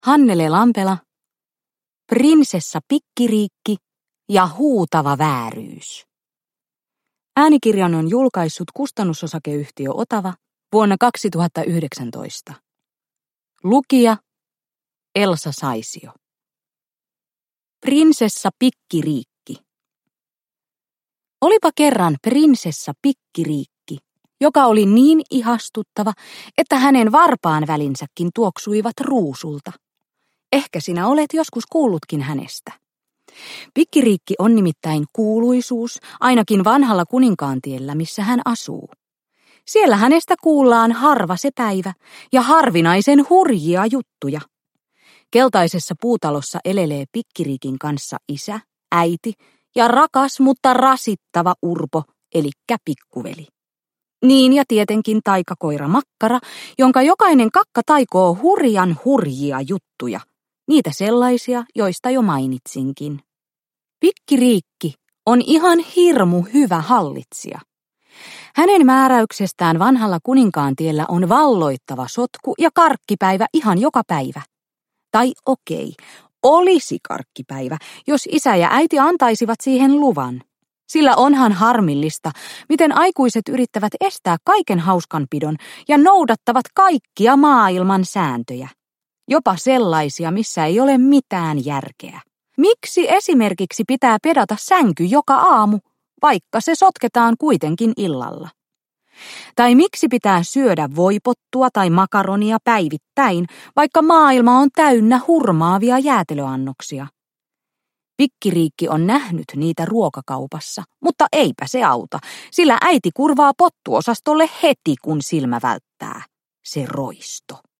Prinsessa Pikkiriikki ja huutava vääryys – Ljudbok – Laddas ner
Uppläsare: Elsa Saisio